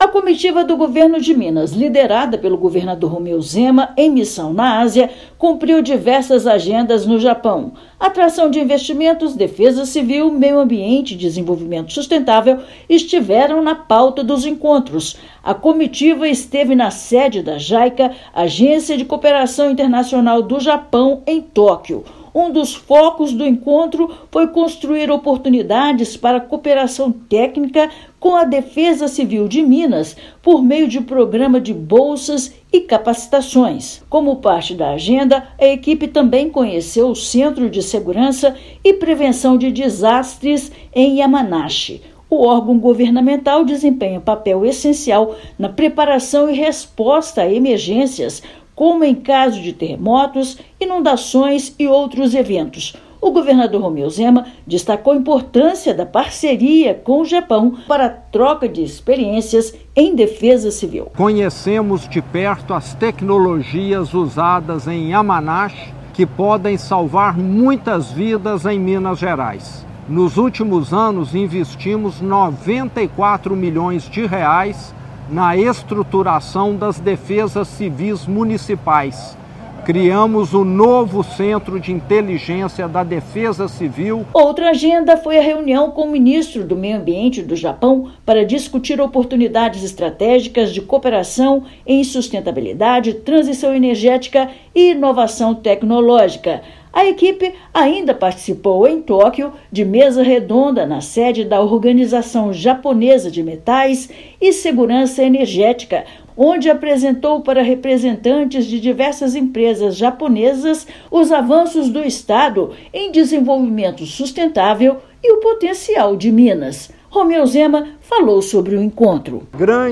Missão mineira no pais asiático apresenta as potencialidades do estado, avanços em desenvolvimento sustentável e busca as melhores práticas de prevenção contra desastres. Ouça matéria de rádio.